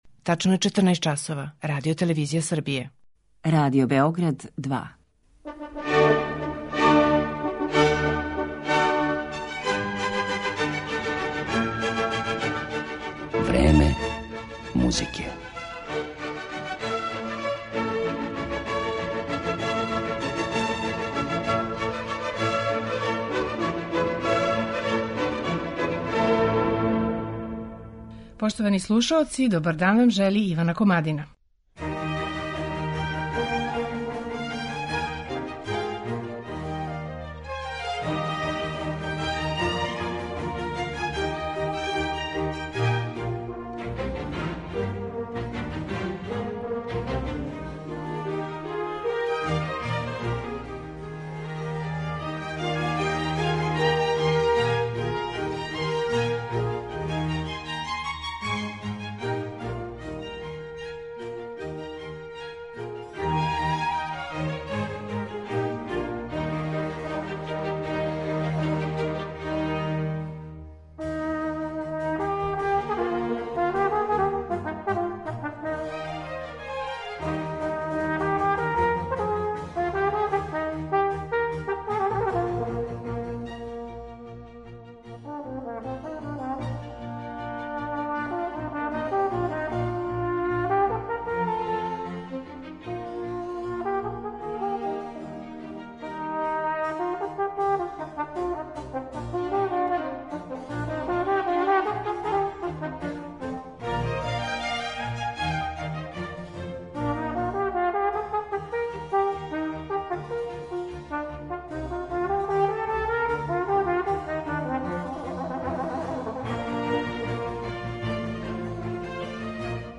Током четири деценије дуге каријере, тромбониста Кристијан Линдберг наметнуо се као солиста на инструменту који се у класичној музици сматра за искључиво оркестарски.